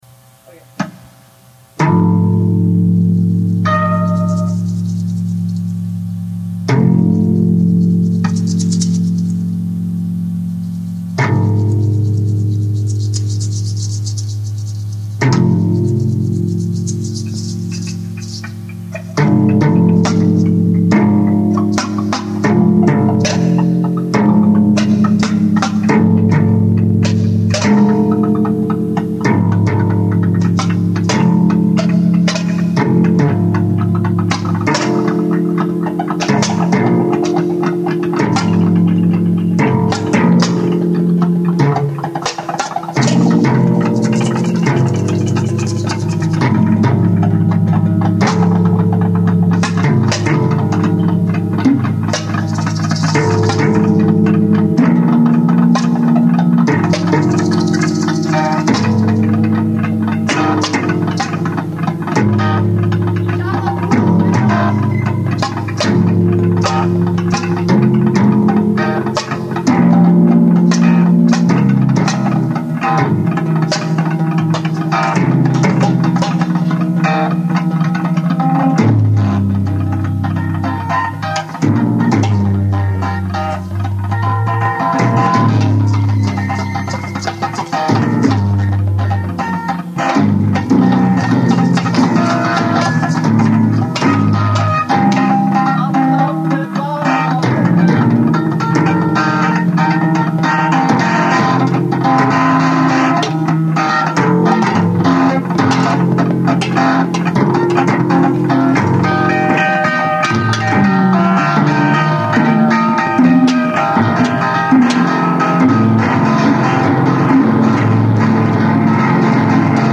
guitar, bass, percussion
bass, vocals, percussion, autovari64, mirage